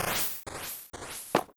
snd_martlet_lands.wav